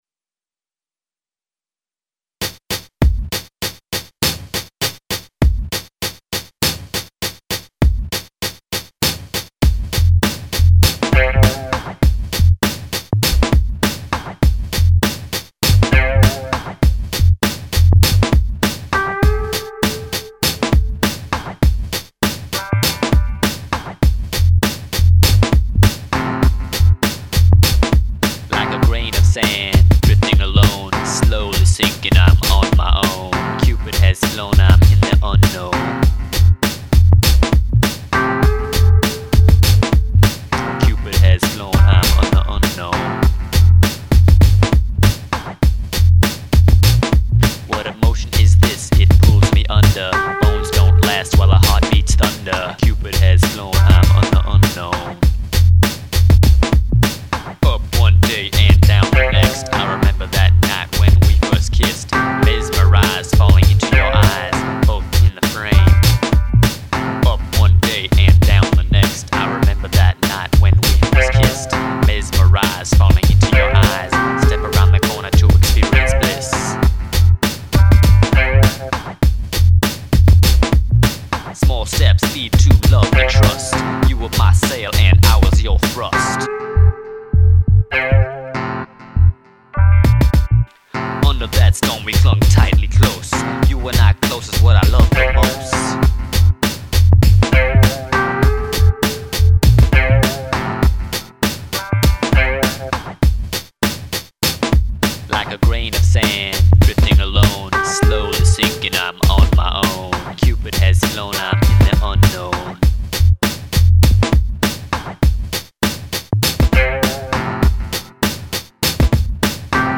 My first attempt at doing Hiphop with rapping.